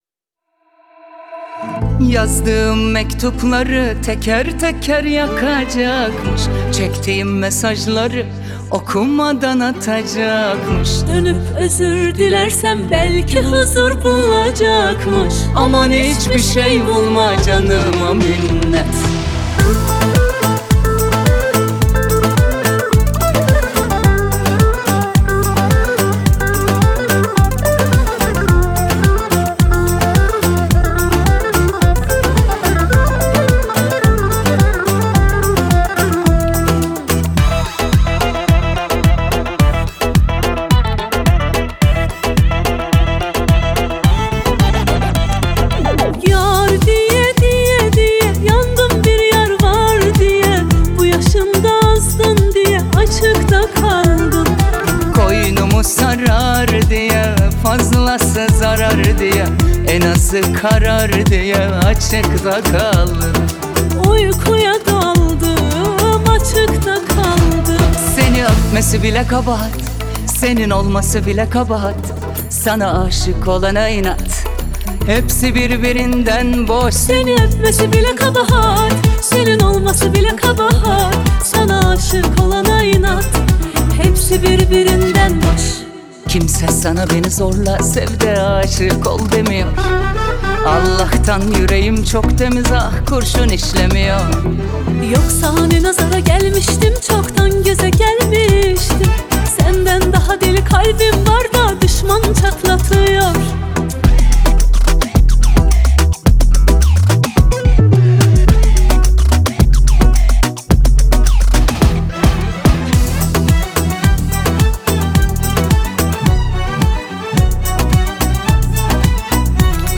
آهنگ ترکیه ای آهنگ شاد ترکیه ای آهنگ هیت ترکیه ای